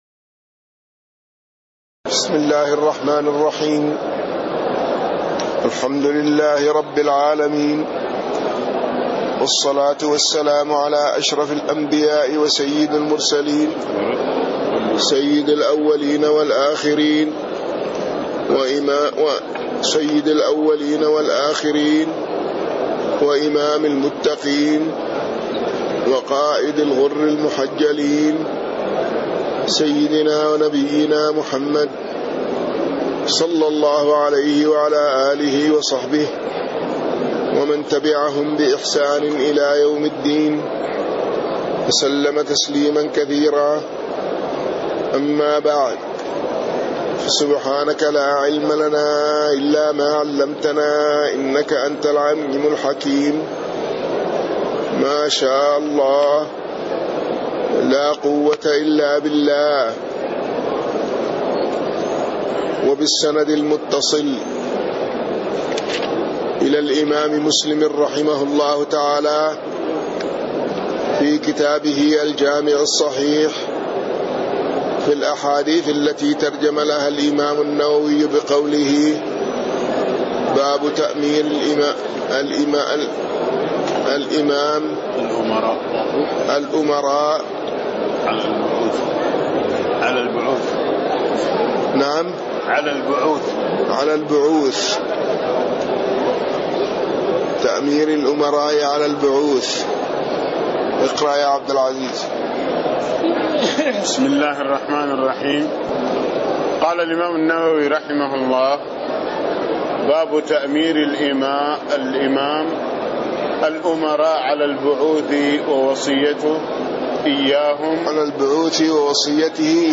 تاريخ النشر ٢٥ شعبان ١٤٣٥ هـ المكان: المسجد النبوي الشيخ